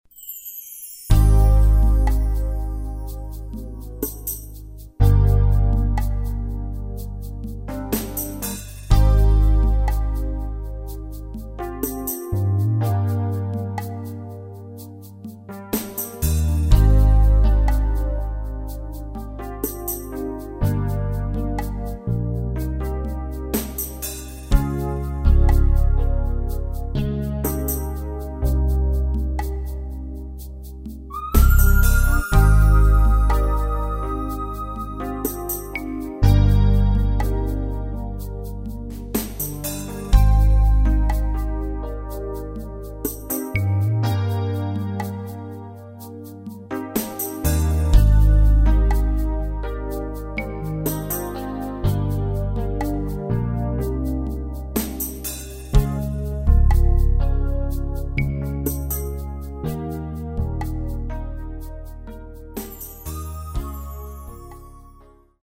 Key of F
Backing track only.